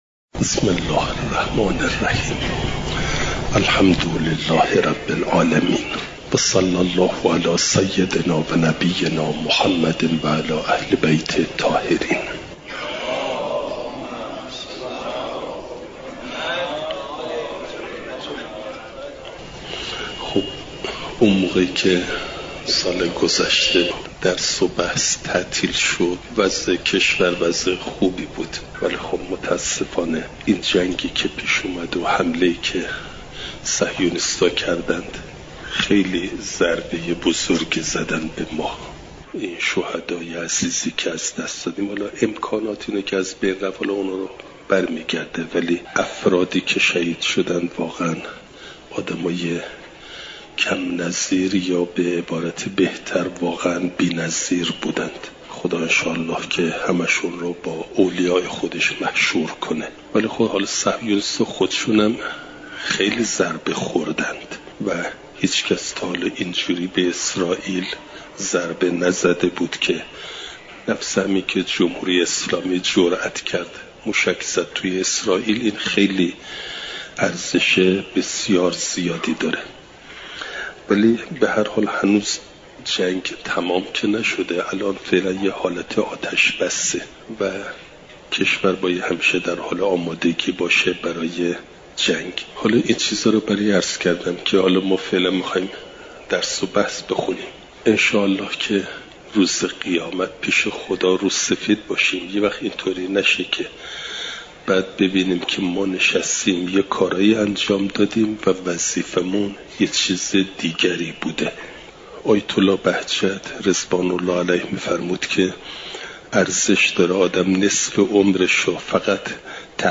سه شنبه ۲۵ شهریورماه ۱۴۰۴، حرم حضرت معصومه سلام ﷲ علیها